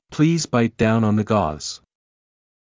ﾌﾟﾘｰｽﾞ ﾊﾞｲﾄ ﾀﾞｳﾝ ｵﾝ ｻﾞ ｶﾞｰｽﾞ